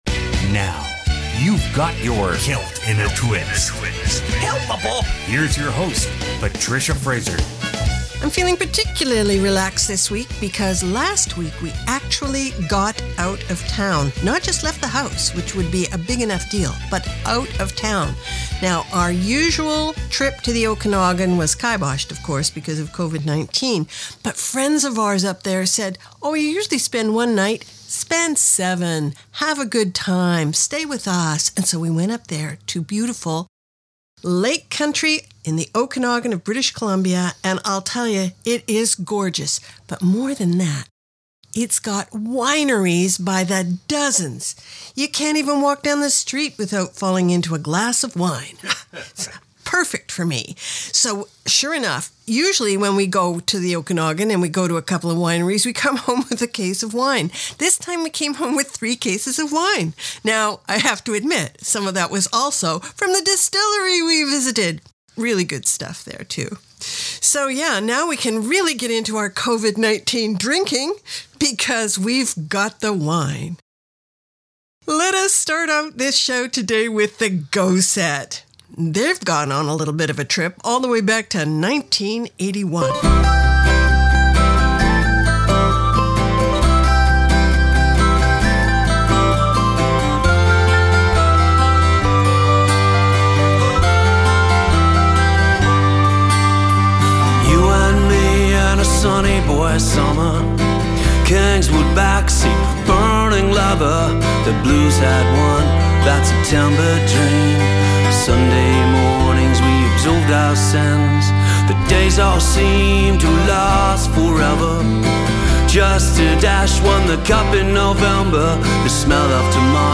Canada's Contemporary Celtic Radio Hour